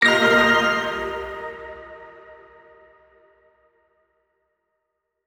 Pickup Magic.wav